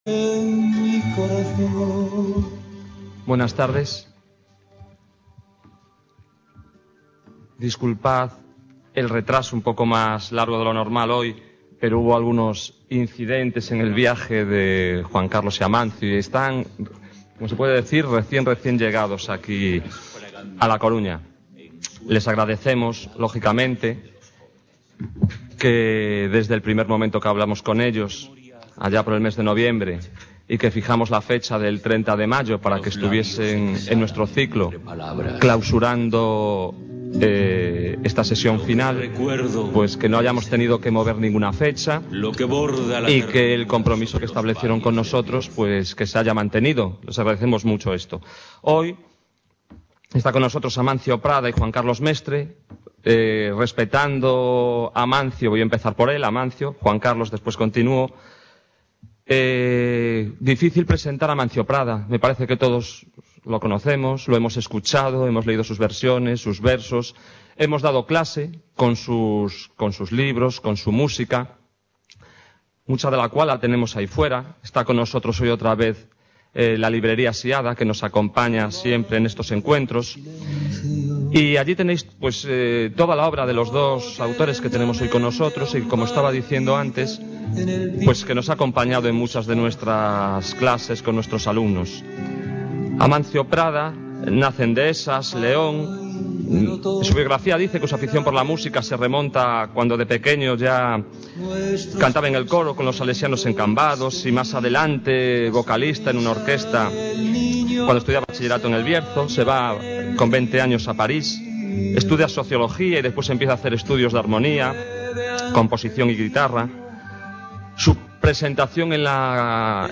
Música y poesía: Juan Carlos Mestre y Amancio Prada.
CEFORE A CORUÑA Juan Carlos Mestre. Poeta, grabador, ensayista. Amancio Prada. Compositor, cantautor, poeta.